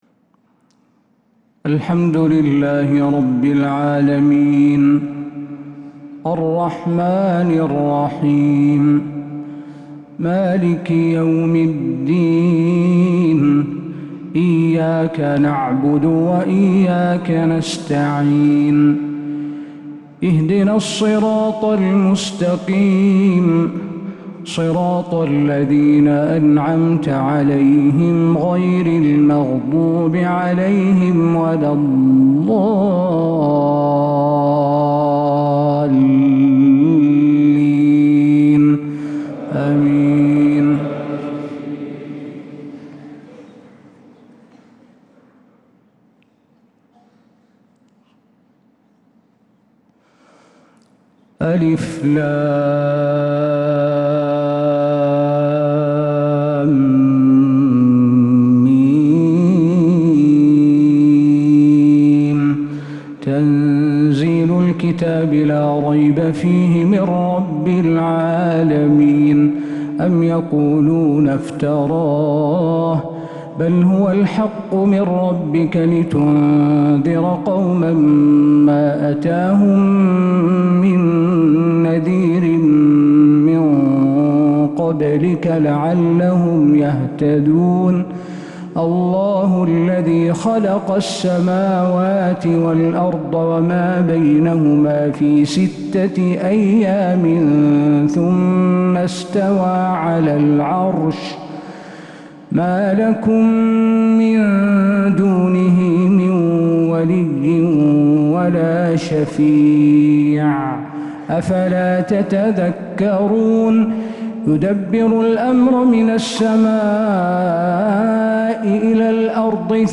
فجر الجمعة 14 صفر 1447هـ | سورتي السجدة و الإنسان | Fajr prayer surah As-sajdah and Al-Insaan 8-8-2025 > 1447 🕌 > الفروض - تلاوات الحرمين